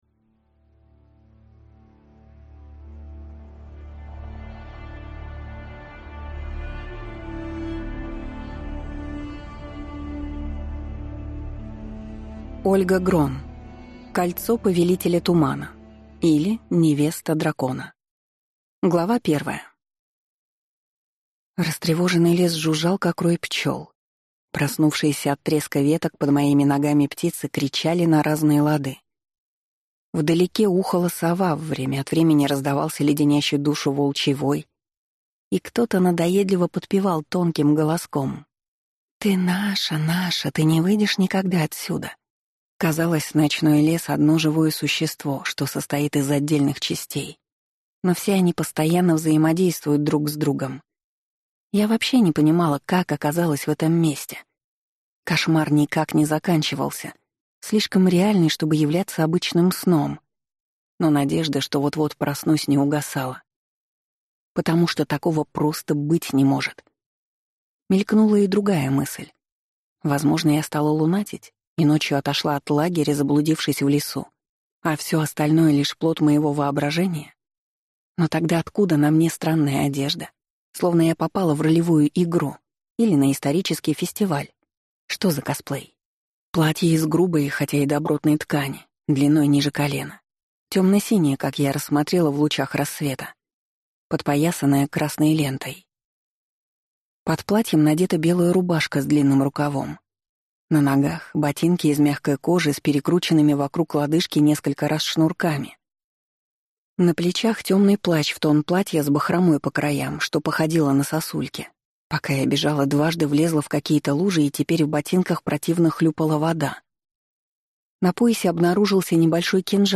Аудиокнига Кольцо повелителя тумана, или Невеста дракона | Библиотека аудиокниг